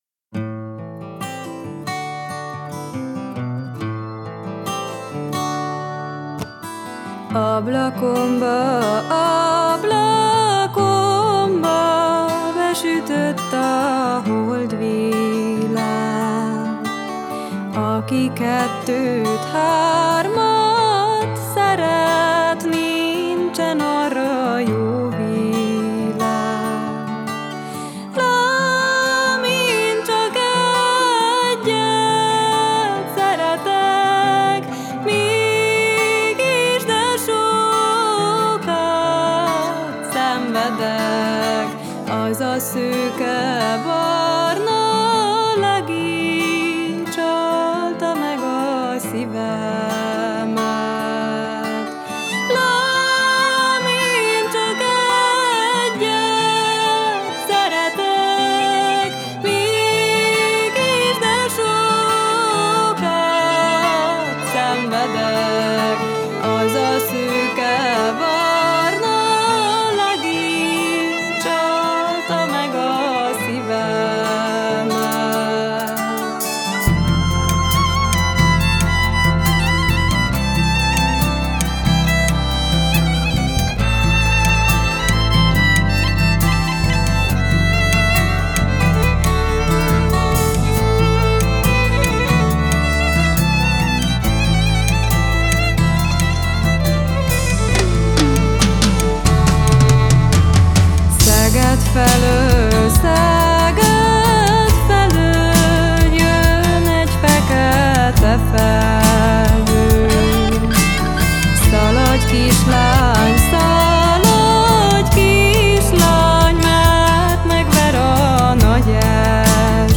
STÚDIÓFELVÉTEL: